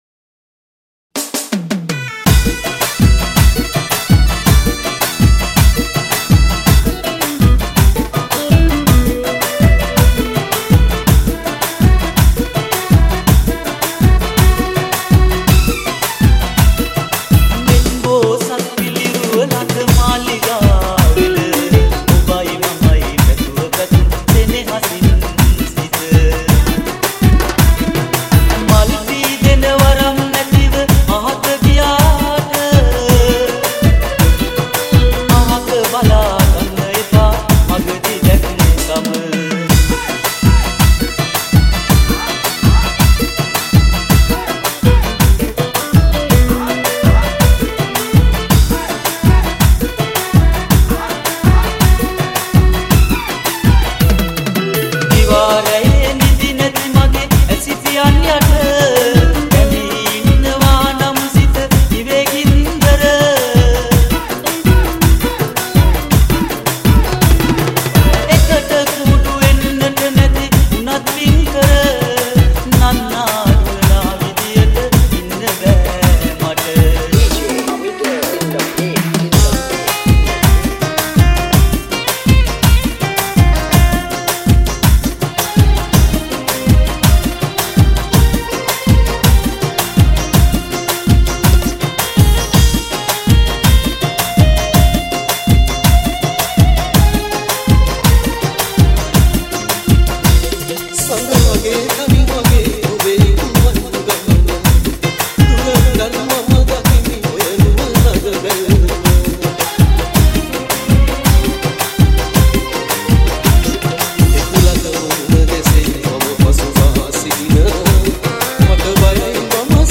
Old Song Nonstop Dj Remix